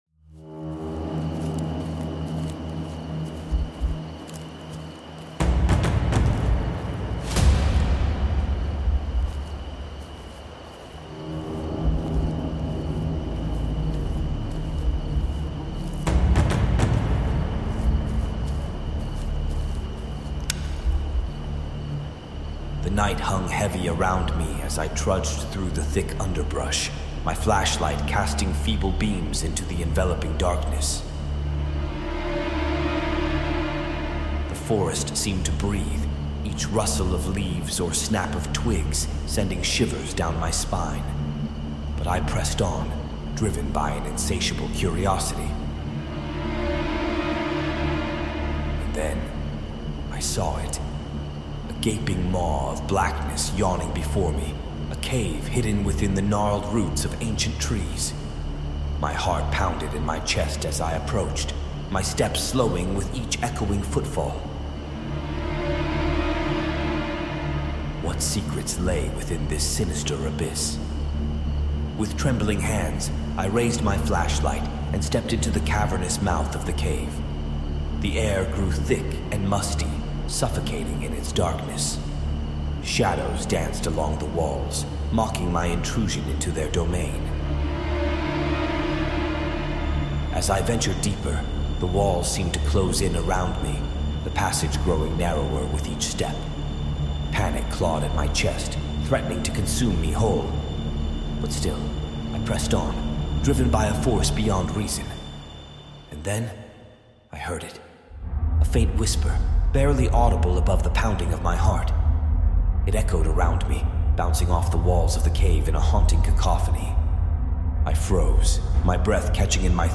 A funny but mostly chilling Drum & Bass horror remix